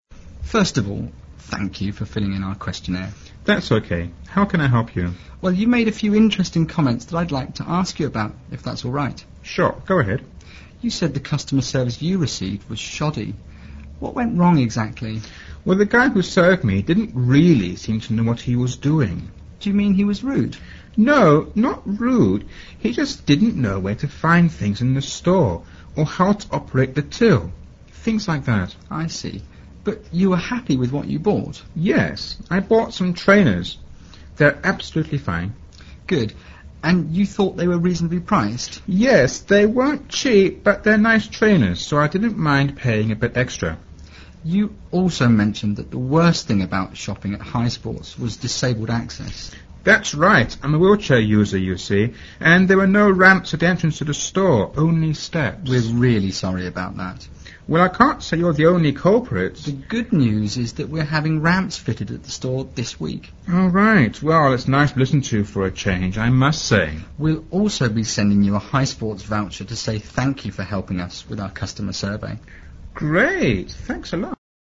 Listen to the dialogue between customer service agent (A) and customer (C)